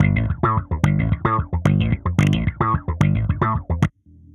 Index of /musicradar/dusty-funk-samples/Bass/110bpm